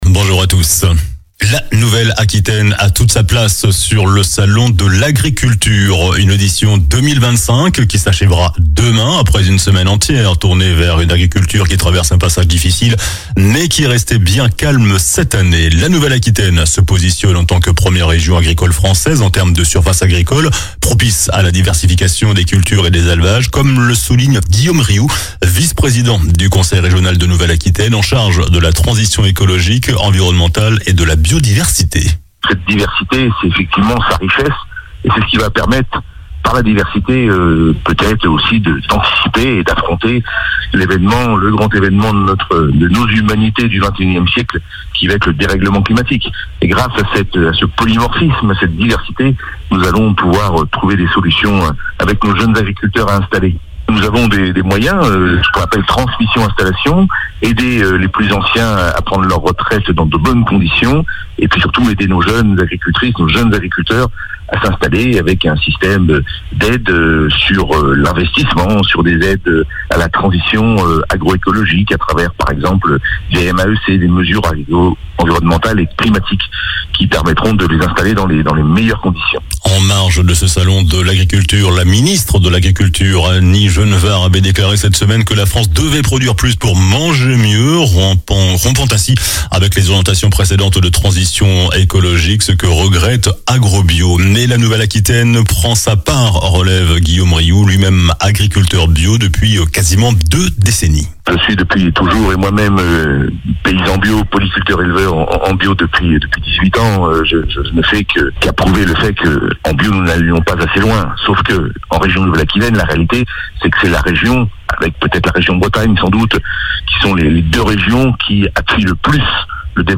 COLLINES LA RADIO : Réécoutez les flash infos et les différentes chroniques de votre radio⬦
JOURNAL DU SAMEDI 01 MARS